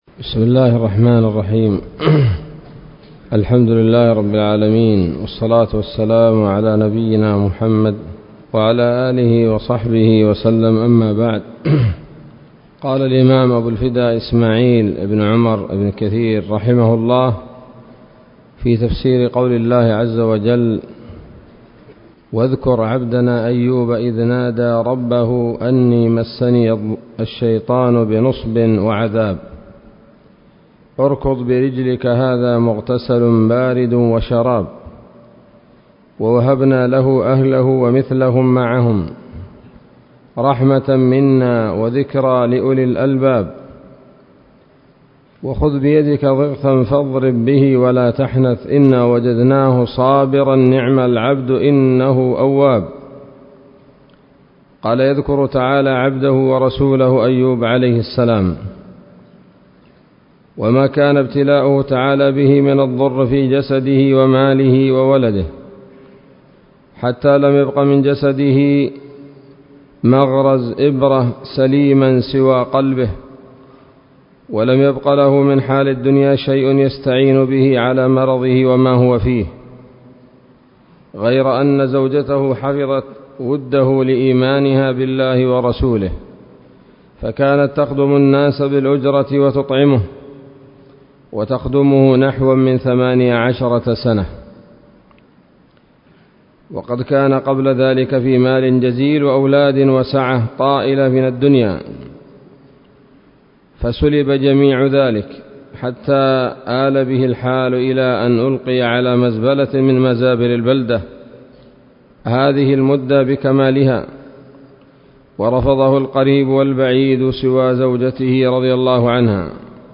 الدرس التاسع من سورة ص من تفسير ابن كثير رحمه الله تعالى